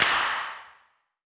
CDK Loud Clap 3.wav